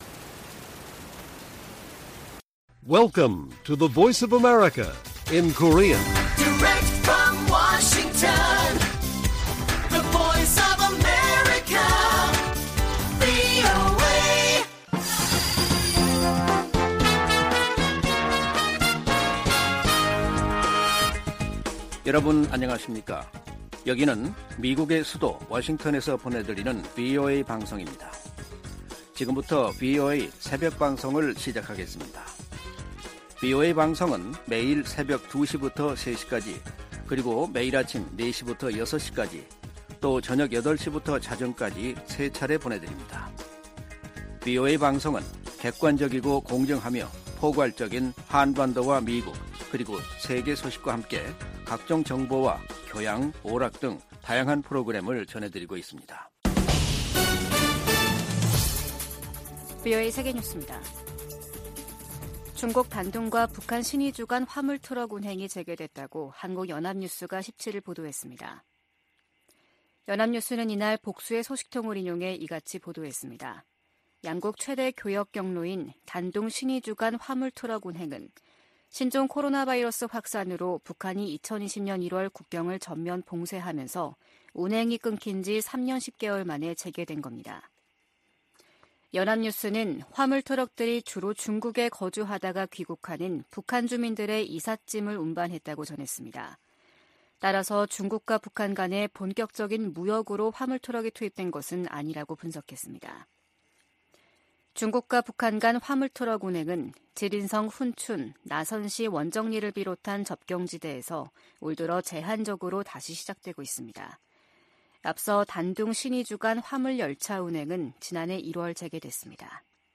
VOA 한국어 '출발 뉴스 쇼', 2023년 11월 18일 방송입니다. 조 바이든 미국 대통령과 기시다 후미오 일본 총리가 타이완해협, 한반도, 동중국해 등에서의 평화와 안정이 중요하다는 점을 재확인했습니다. 미국은 동맹국의 핵무기 추구를 단호히 반대해야 한다고 국무부의 안보 관련 자문위원회가 주장했습니다. 미중 정상이 양국 간 갈등을 완화하는 데 동의함으로써 한반도 안보 상황에도 긍정적으로 작용할 것이라는 관측이 나오고 있습니다.